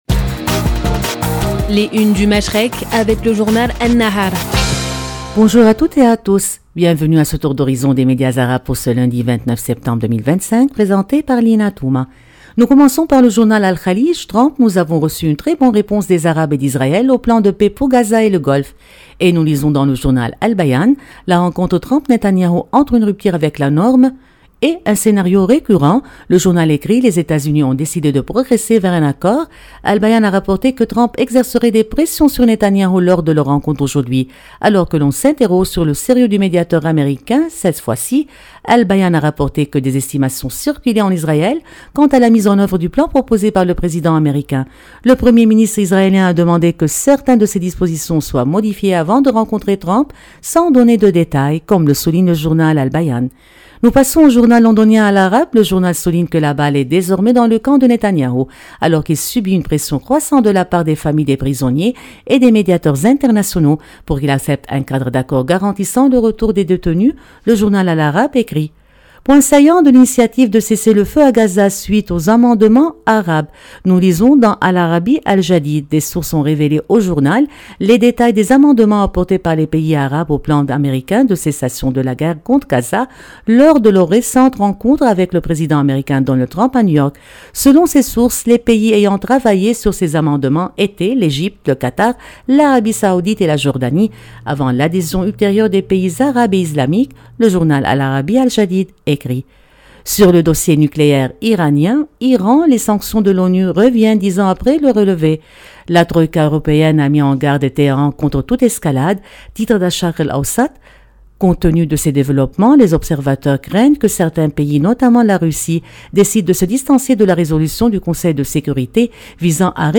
Revue de presse des médias arabes